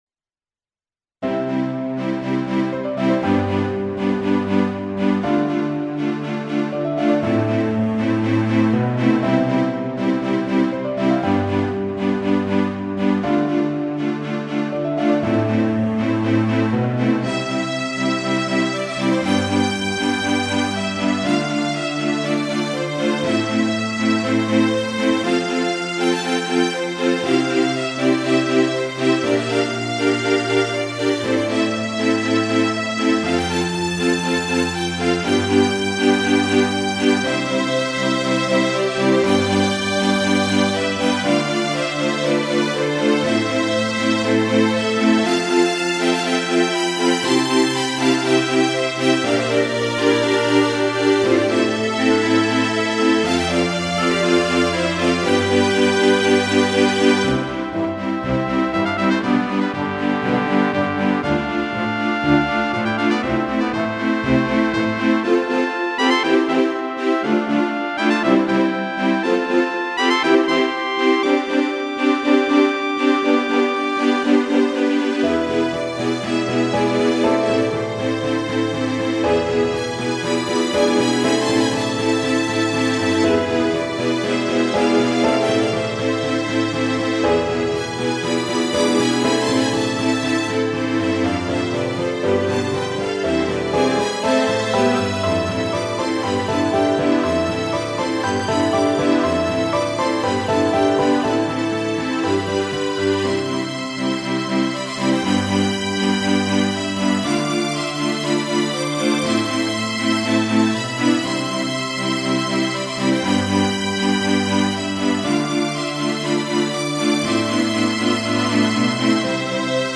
YAMAHA MU90にて録音(2.78 MB)   ええっと、3年半寝てました。
今回は、できるだけ、五月蝿くならんよう、がんばってみました。
構成は、ピアノとか弦とか金管とか木管とか。
おお、では、これは、盛大に壮大な曲かと思いきや、しょぼんとした音が脱力感を誘います。